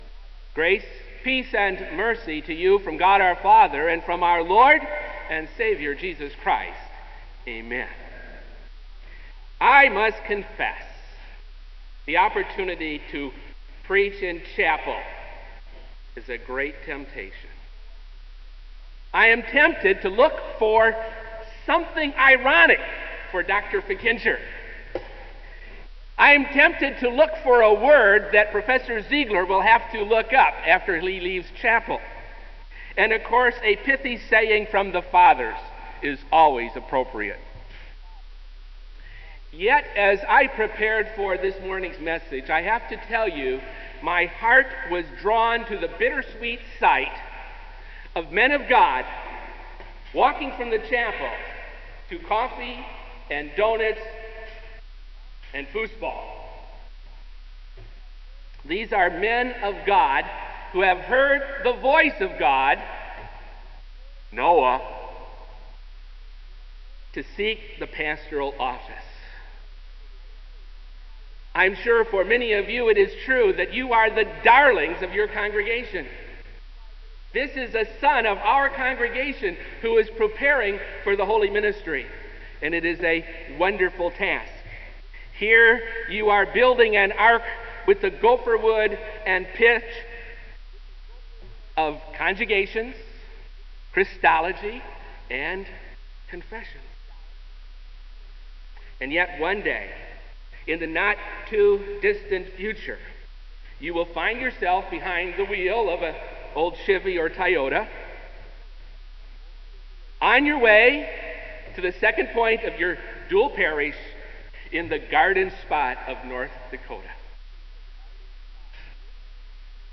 Kramer Chapel Sermon - February 04, 2002